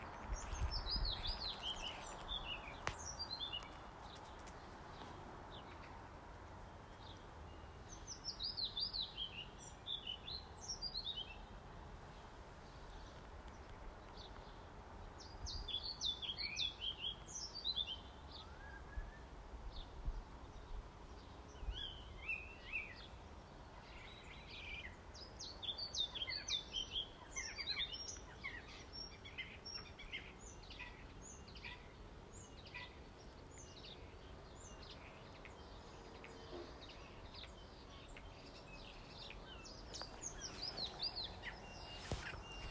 Denne fuglen synger hos oss på Nøtterøy hver dag, vet dere hvilken fugl dette er?
Dette er en usedvanlig virtuos svarthvit fluesnapper.